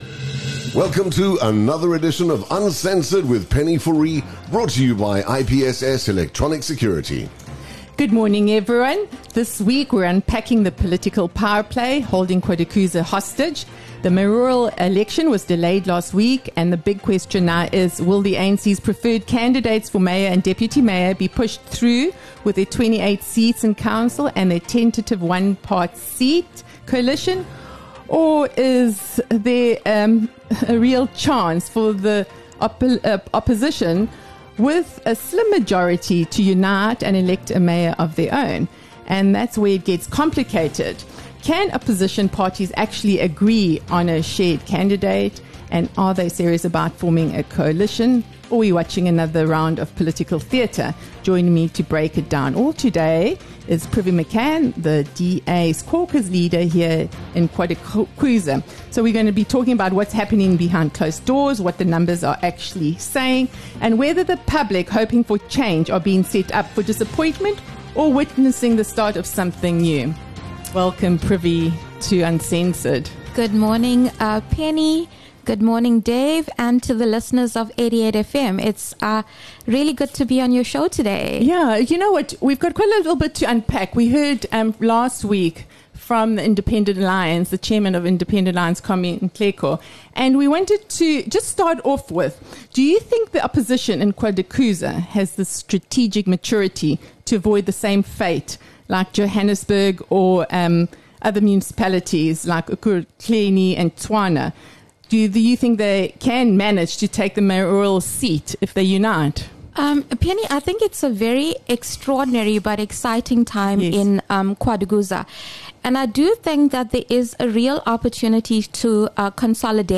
But here’s the real question: Would the DA be open to backing a compromise candidate, someone not from their party if it means ending the ANC’s hold? In this week’s Uncensored, I speak to DA caucus leader Privi Makhan about what’s unfolding behind closed doors, and whether coalition talks are real or just more political theatre.